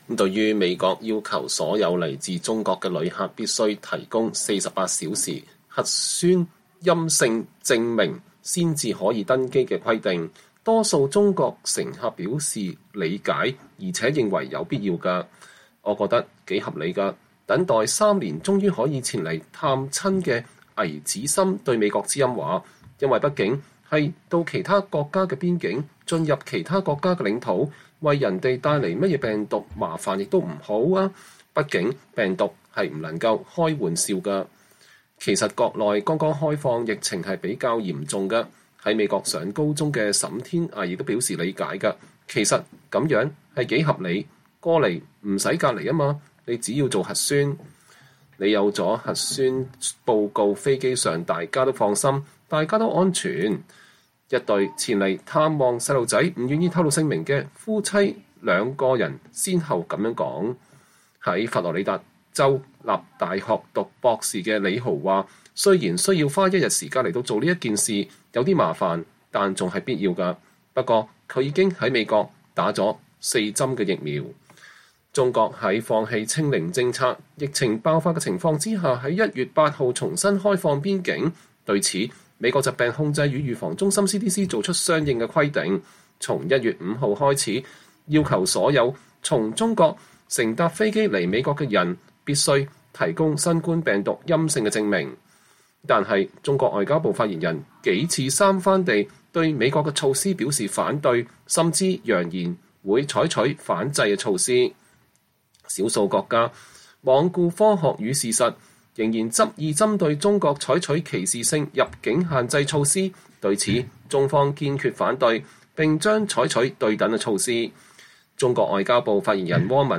在紐約肯尼迪國際機場接受採訪的來自上海的旅客，告訴了他們為搭乘航班做核酸檢測的經驗。